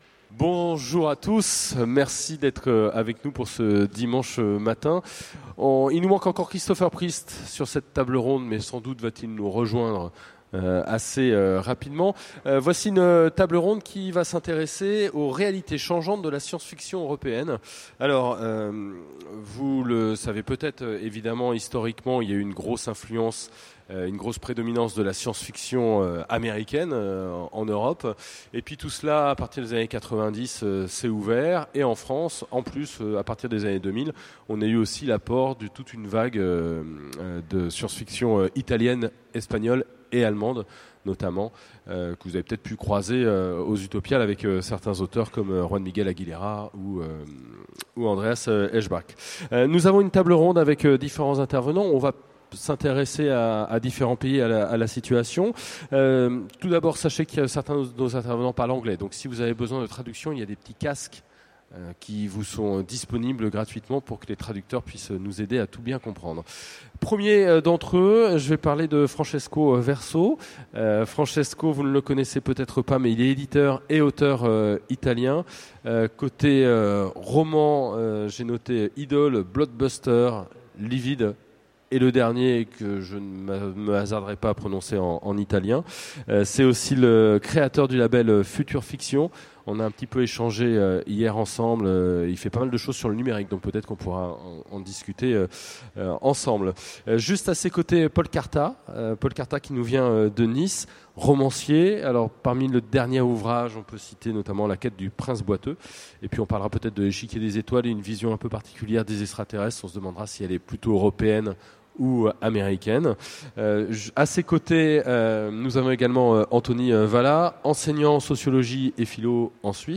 Utopiales 2015 : Conférence Les réalités changeantes de la science-fiction européenne